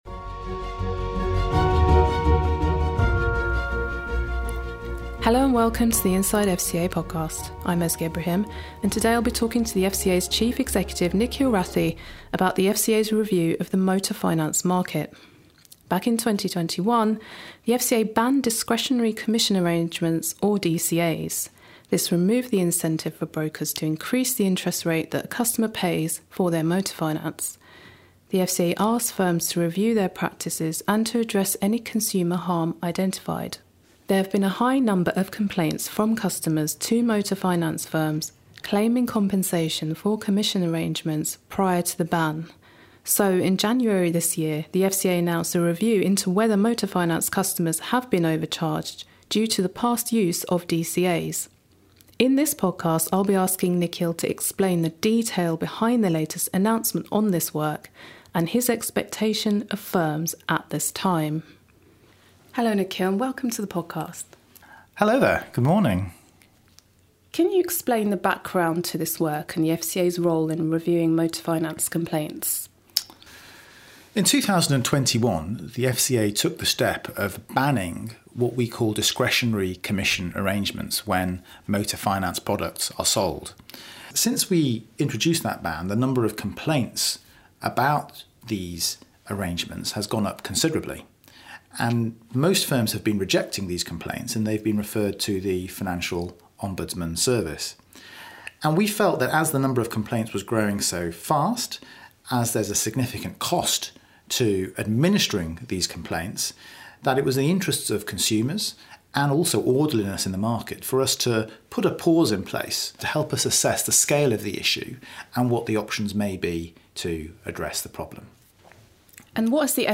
This exclusive Inside FCA Podcast interview with Chief Executive, Nikhil Rathi, explains the detail behind the FCA's proposal to pause motor finance complaint handling. In the podcast, Nikhil outlines his expectations of firms and advice for consumers.